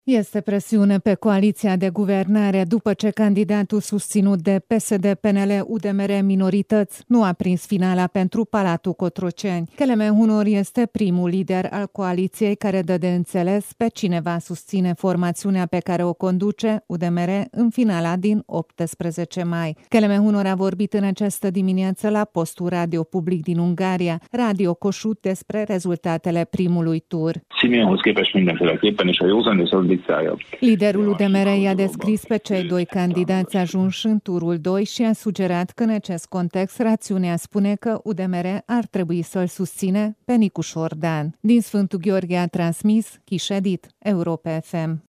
Kelemen Hunor a vorbit în această dimineață la postul radio public din Ungaria  – Radio Kossuth – despre rezulatele primului tur.